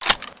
assets/psp/nzportable/nzp/sounds/weapons/gewehr/magin.wav at 29b8c66784c22f3ae8770e1e7e6b83291cf27485